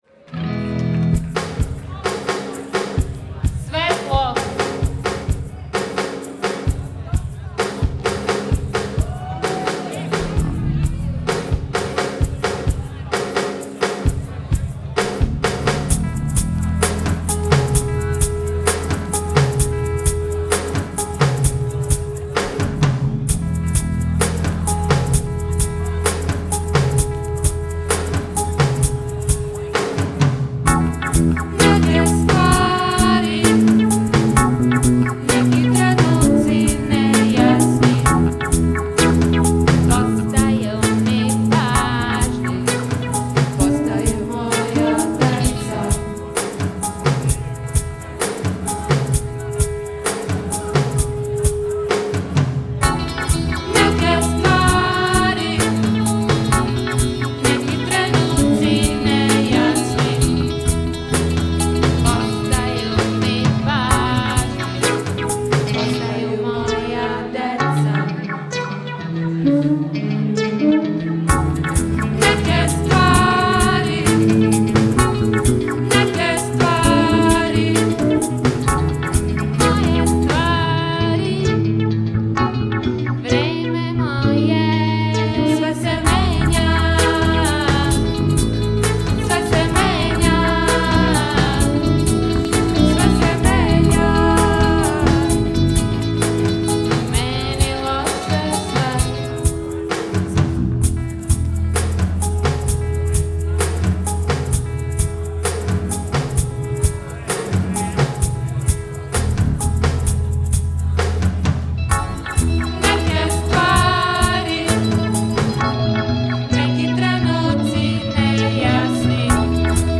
Godinama rade kao elektronsko-akustični sistem i DJ tim
live